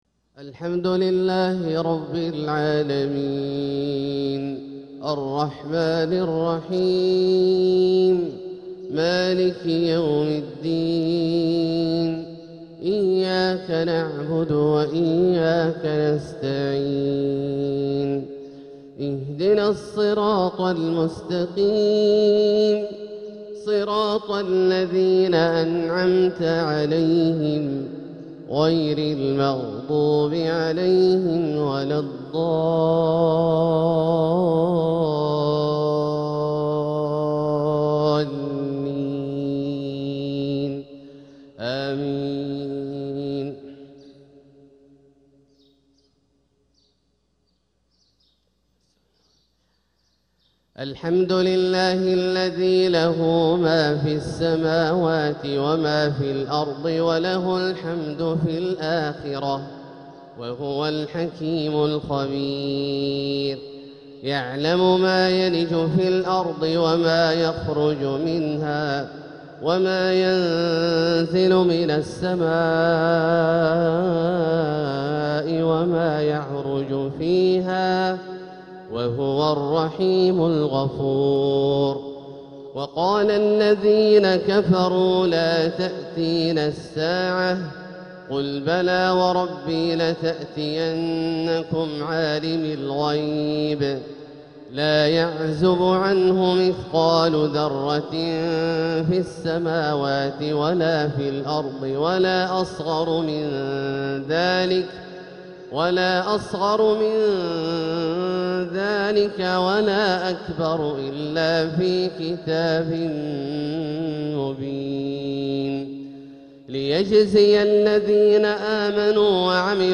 فجر الأربعاء 5-6-1447هـ | فواتح سورة سبأ 1-14 | > ١٤٤٧هـ > المميزة - تلاوات عبدالله الجهني